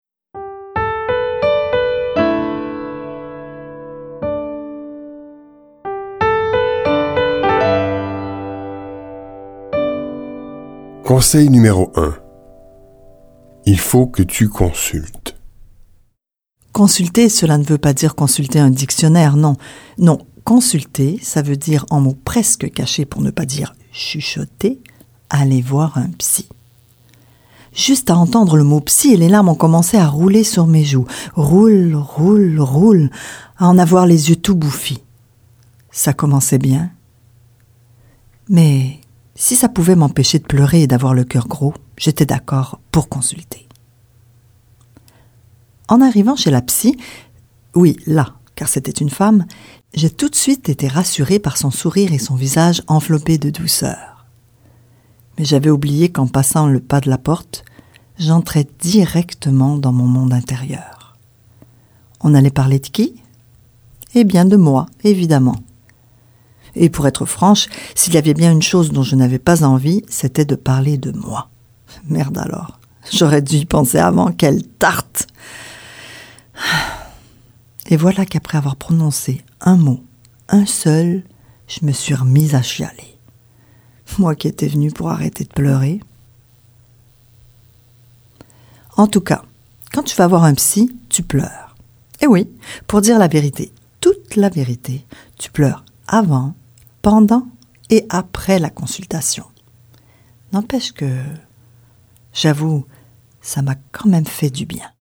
« Dépression » est un livre audio rempli de petits conseils. Oui, des conseils livrés avec douceur et émotion.